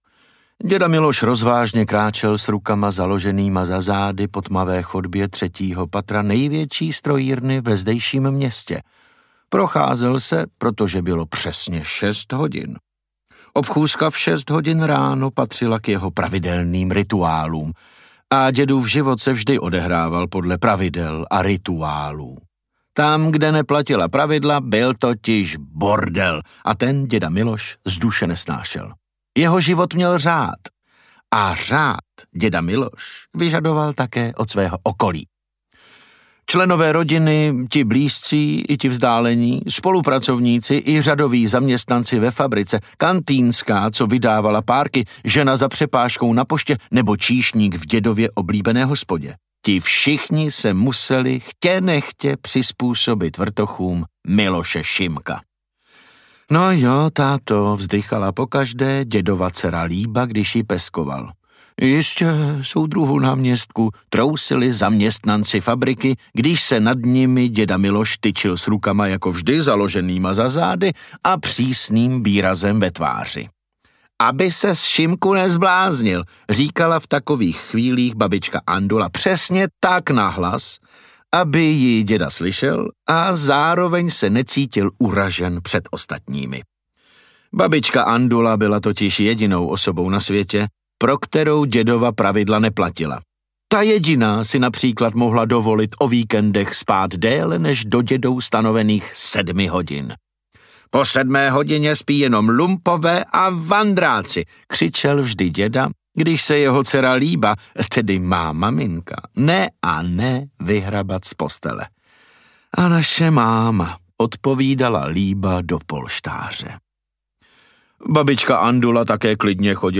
Audiobook
Read: David Novotný